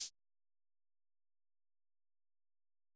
UI Click 2.wav